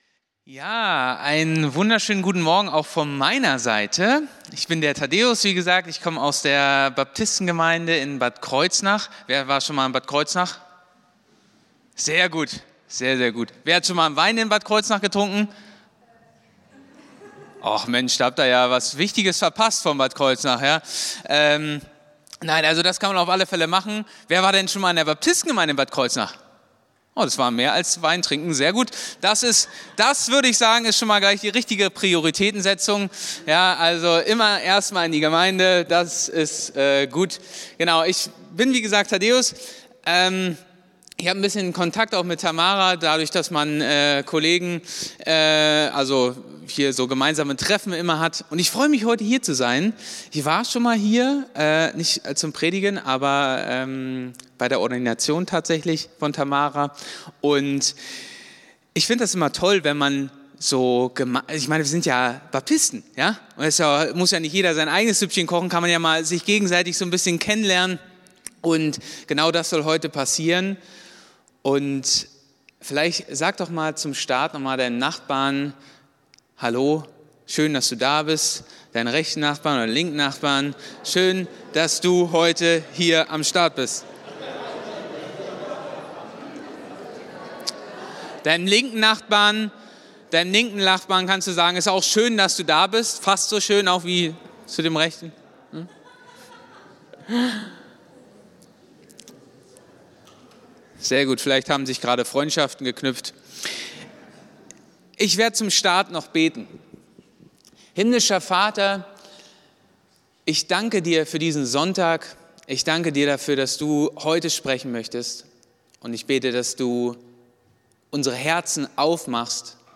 Predigt vom 19.03.2023